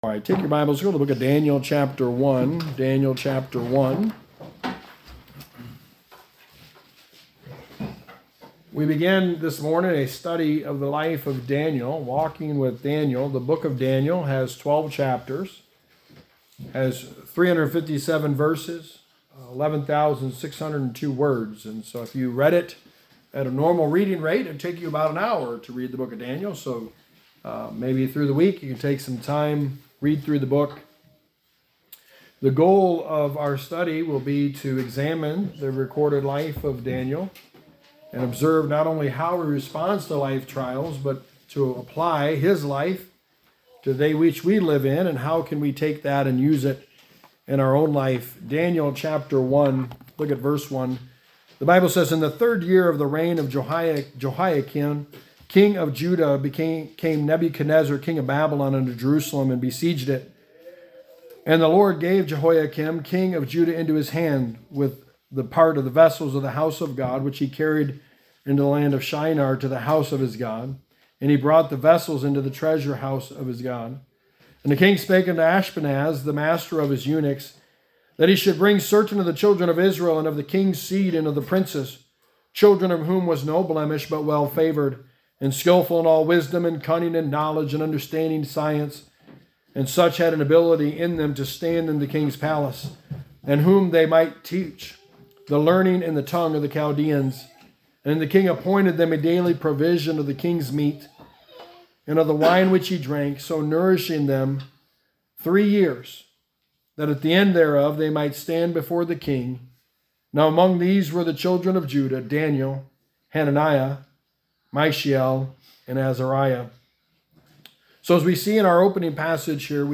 Walking With Daniel – A study of the life of Daniel Sermon #1: Are You Confident?
Daniel 1:1-6 Service Type: Sunday Morning Walking With Daniel